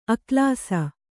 ♪ aklāsa